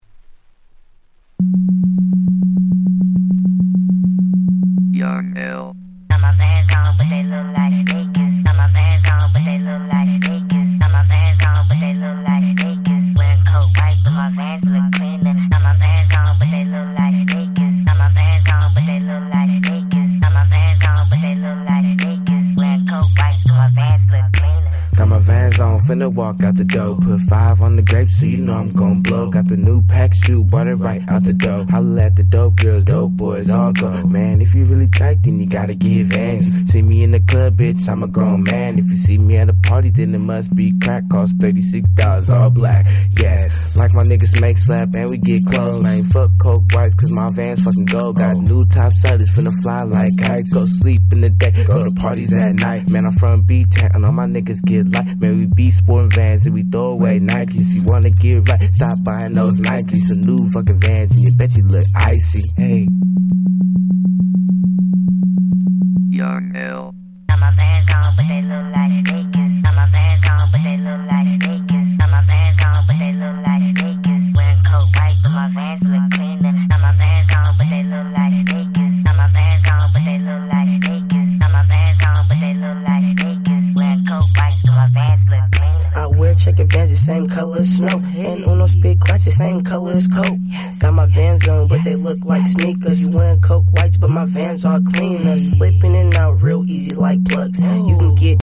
06' Smash Hit Southern Hip Hop !!
当時一瞬流行って一世を風靡した、いわゆる「ささやき系」なスーパーヒット！！
00's サウス